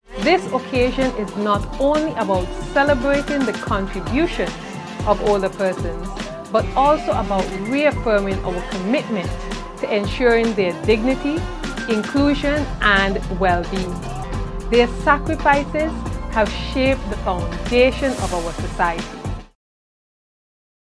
Nevis’ Minister of Health & Social Services, the Hon. Jahnel Nisbett. Her comments were made in an address as part of the observance of International Day of Older Persons, which is observed annually on October 1st.